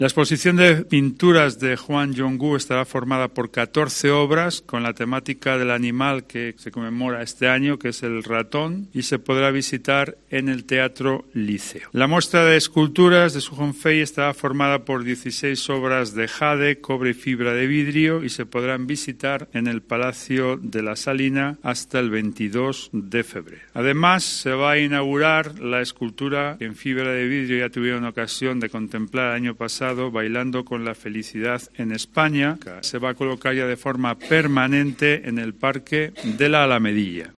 El alcalde Carlos García Carbayo informa sobre el programa de actividades